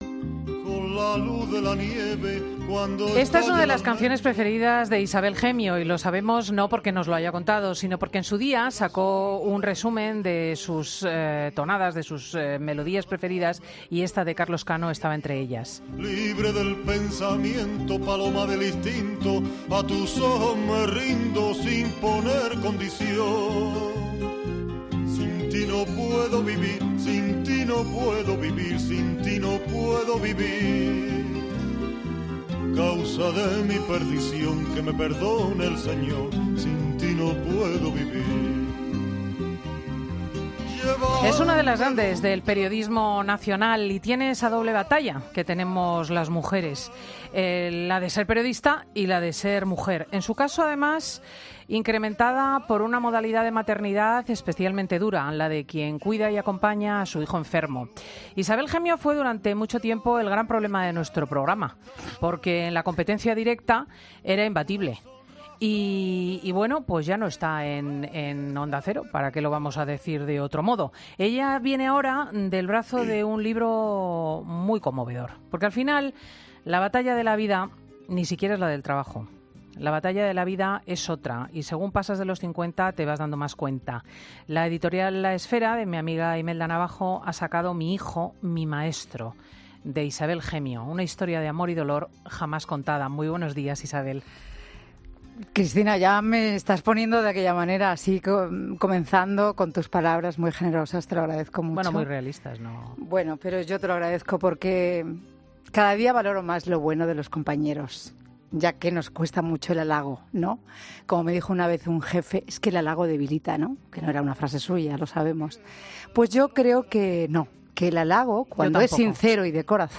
Entrevistamos a la periodista y autora de 'Mi hijo, mi maestro'